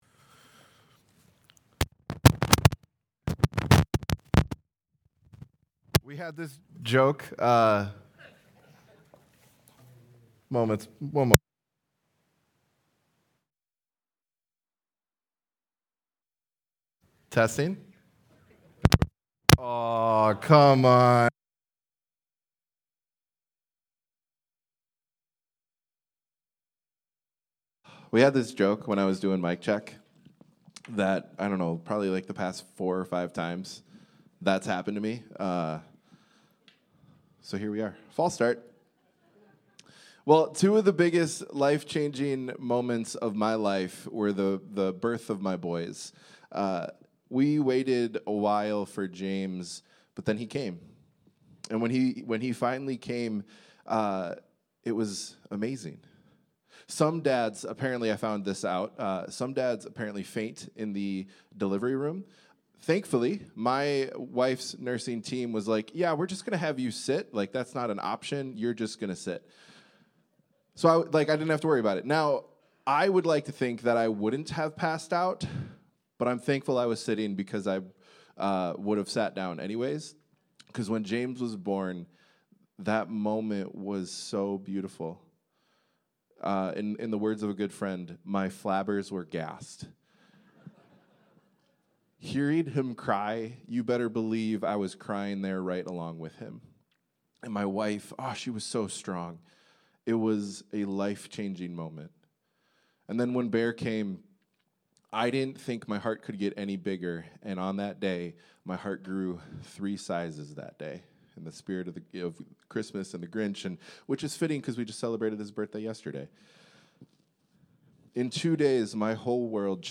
Sunday Sermon: 12-7-25